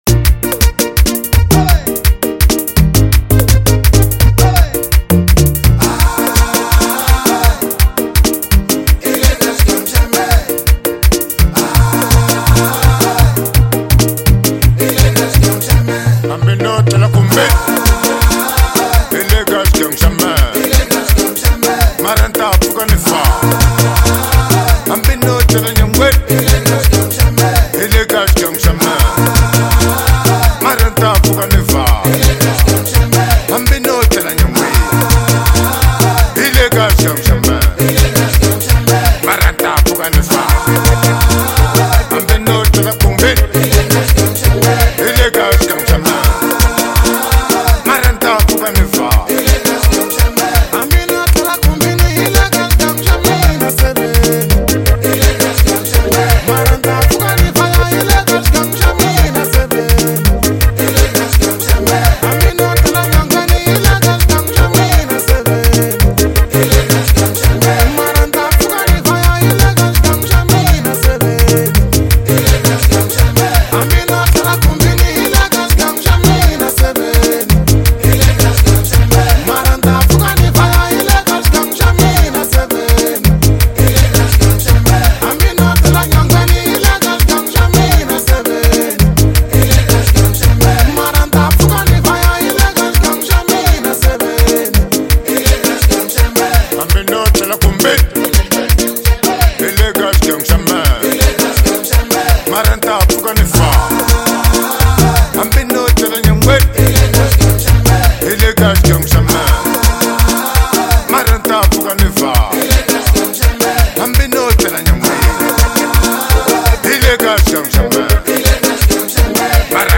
is a heartfelt,culturally rich anthem
leads the track with emotional clarity